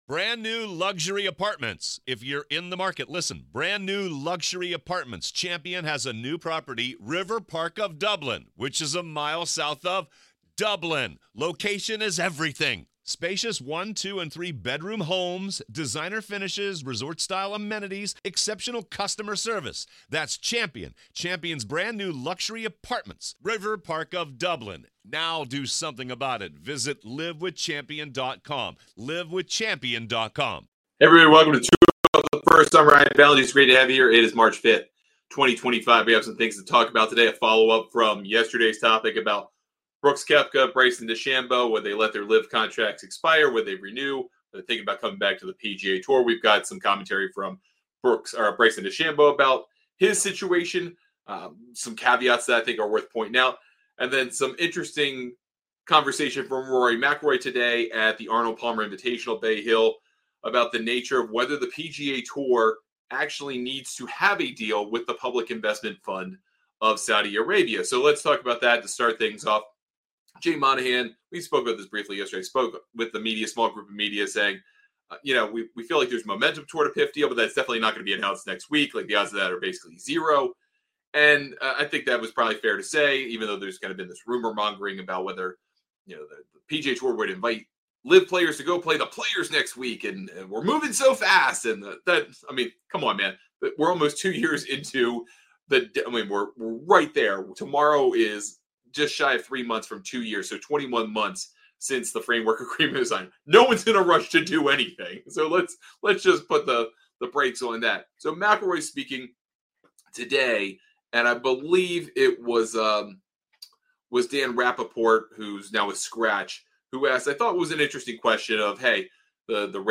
On today's LIVE show